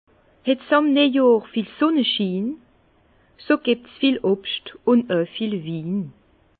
Bas Rhin
Herrlisheim